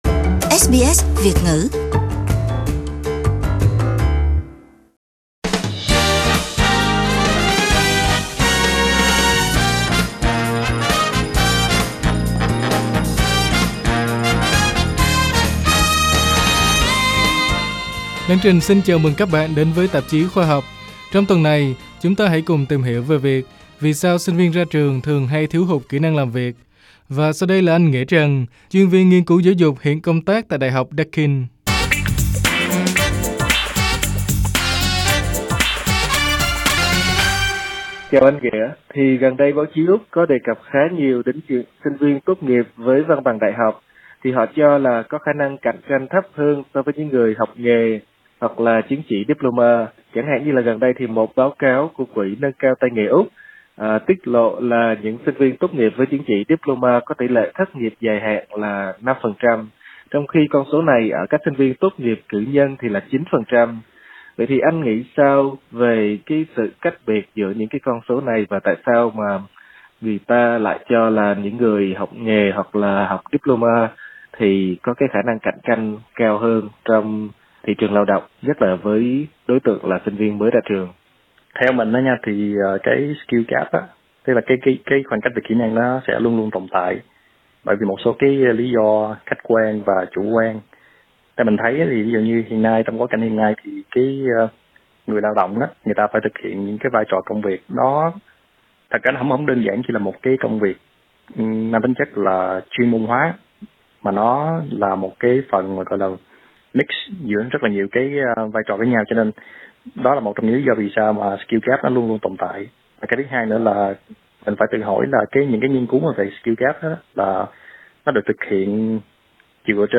Sở hữu một tấm bằng đại học vẫn luôn được xem là chìa khóa để bước vào đời và có được công việc ổn định, thế nhưng nghiên cứu từ Quỹ Nâng cao Tay nghề Úc lại cho thấy, những sinh viên tốt nghiệp với chứng chỉ diploma lại có tỉ lệ thất nghiệp dài hạn thấp hơn sinh viên đại học. SBS Việt ngữ phỏng vấn